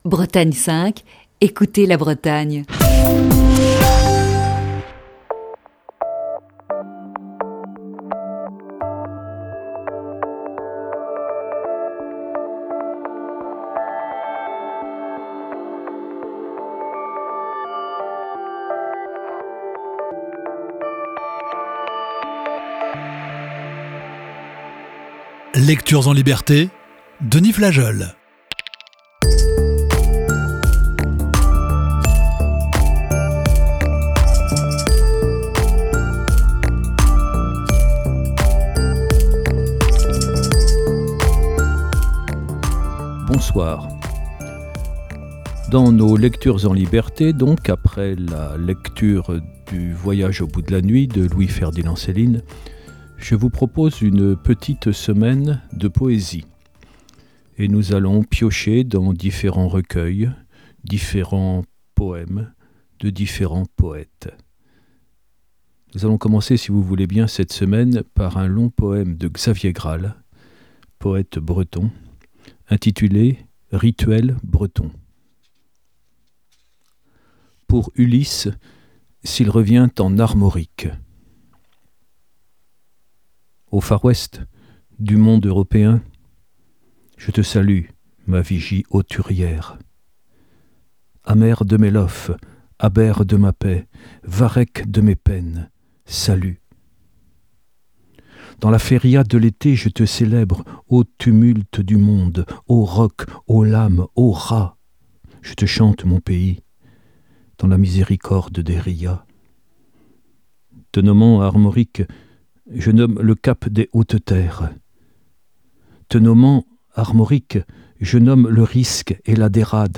Émission du 17 février 2020.